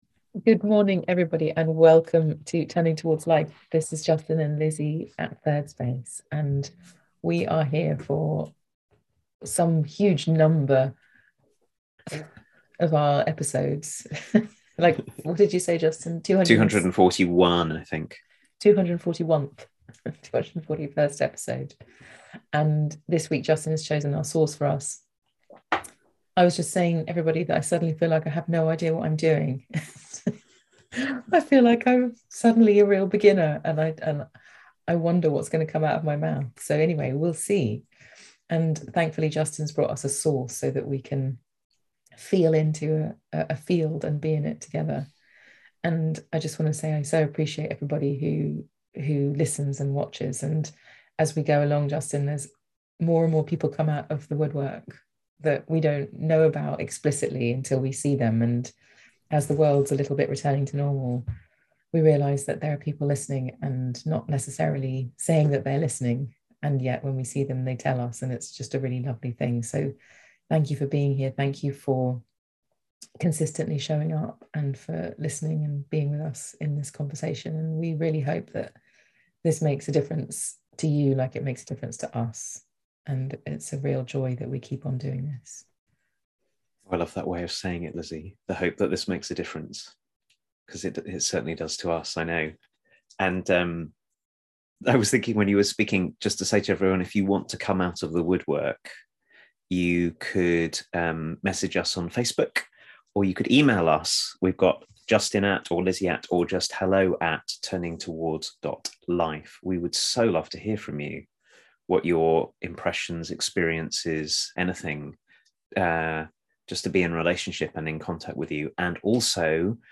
This week's Turning Towards Life is a conversation about a grown up love we might cultivate over time, a path to walk, that is deeper and more inclusive.